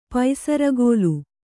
♪ paisaragōlu